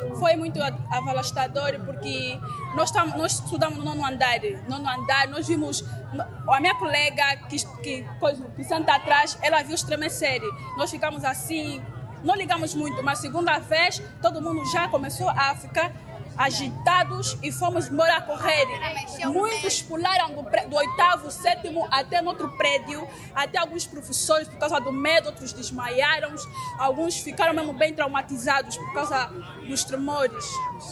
Uma das estudantes contou como foi a reação que tiveram no momento.